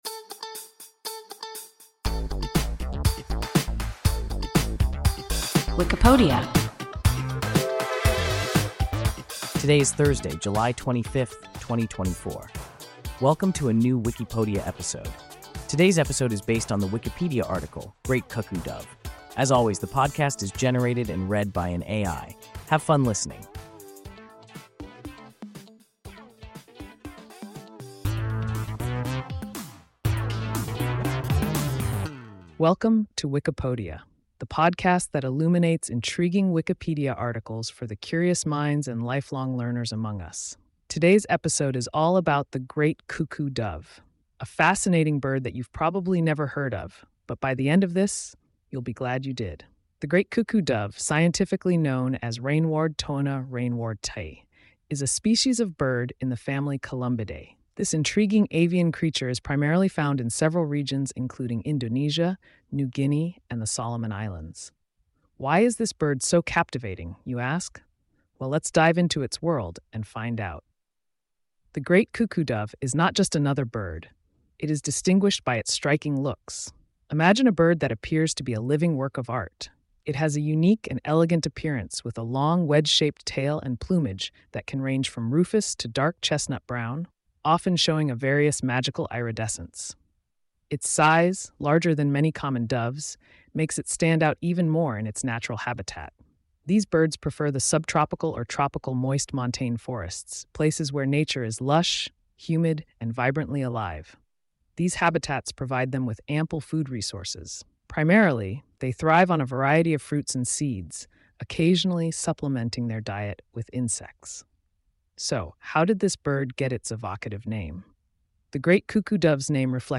Great cuckoo-dove – WIKIPODIA – ein KI Podcast